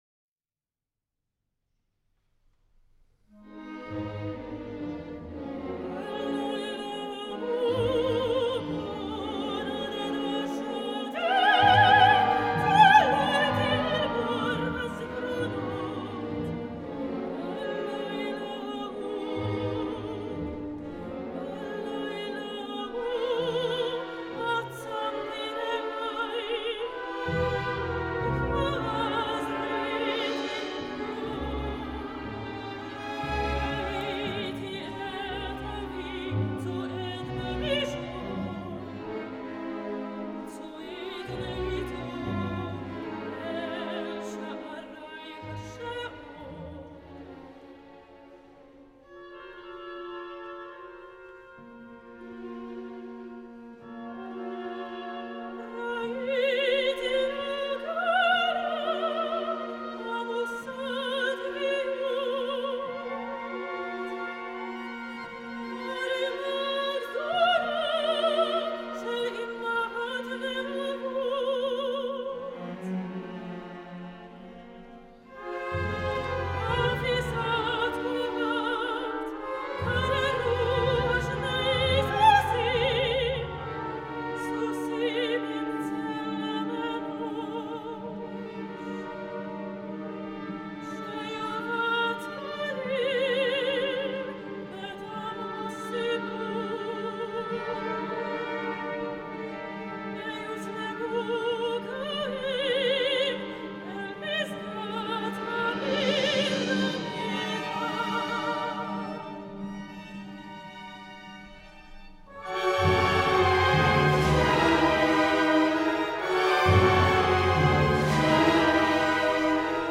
a song cycle for soprano solo and Symphony Orchestra.